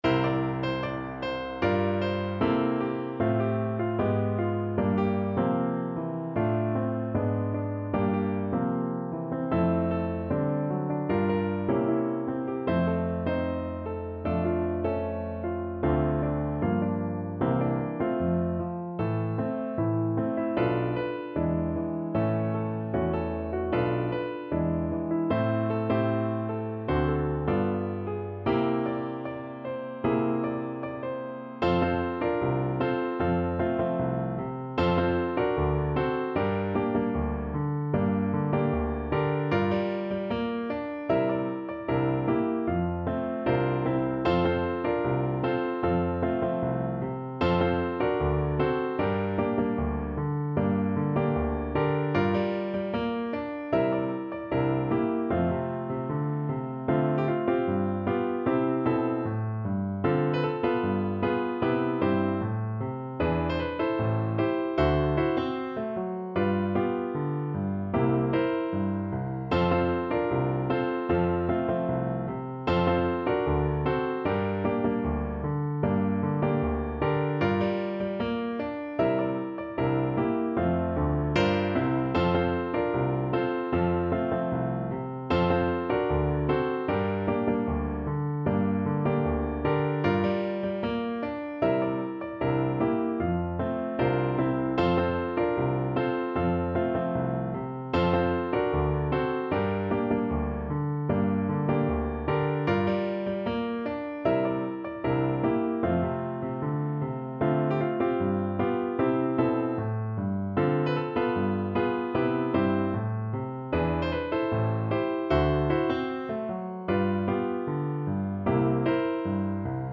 Pop (View more Pop Voice Music)